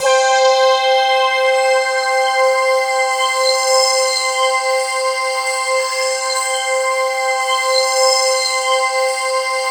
BRASSPADC5-R.wav